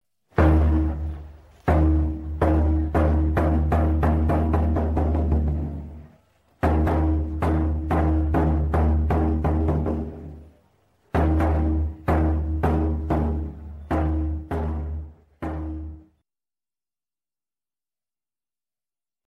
Tiếng Trống Khai Giảng chào đón năm học mới… (Sample 2)
Thể loại: Tiếng động
Âm thanh này mang tính biểu tượng, tạo bầu không khí hân hoan, khích lệ và truyền cảm hứng cho học sinh, giáo viên. Hiệu ứng âm thanh trống vang vọng, dồn dập, trang nghiêm nhưng đầy phấn khởi, rất thích hợp dùng làm sound effect trong video khai giảng, phim tư liệu học đường hoặc clip chào mừng năm học.
tieng-trong-khai-giang-chao-don-nam-hoc-moi-sample-2-www_tiengdong_com.mp3